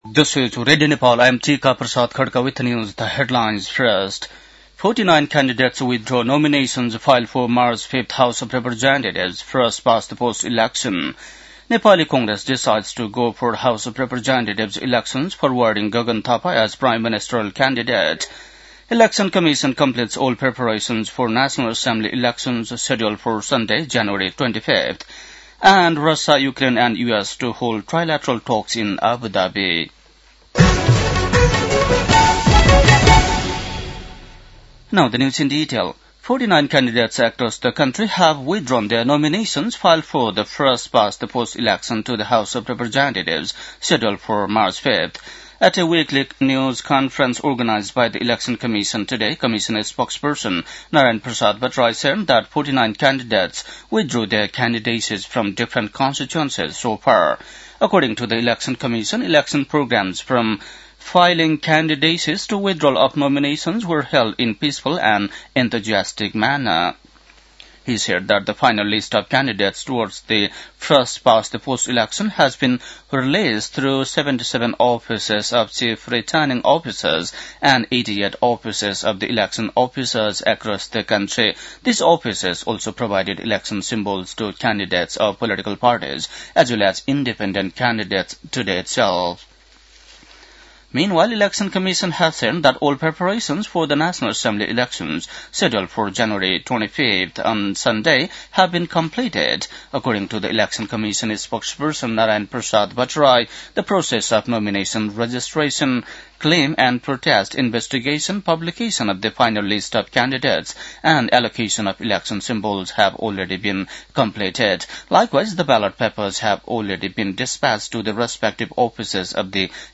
बेलुकी ८ बजेको अङ्ग्रेजी समाचार : ९ माघ , २०८२
8-pm-english-news-10-09.mp3